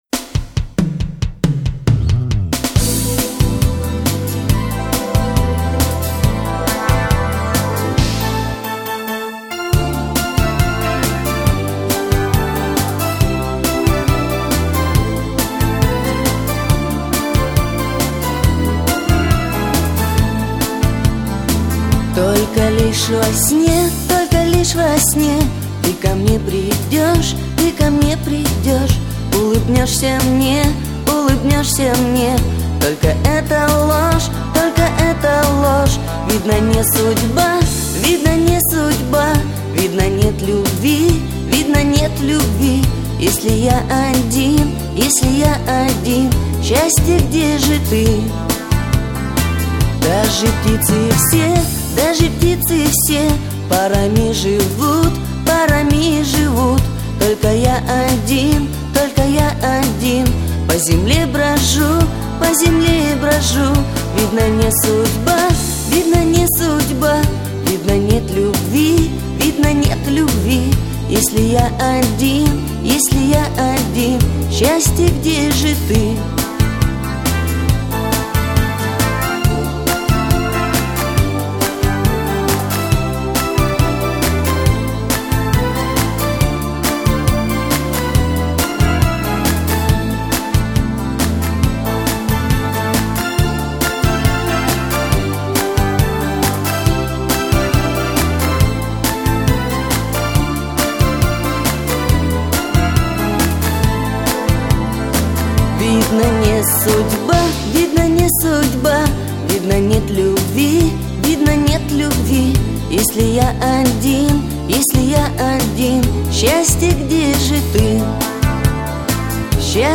Жанр: Шансон, размер 2.40 Mb.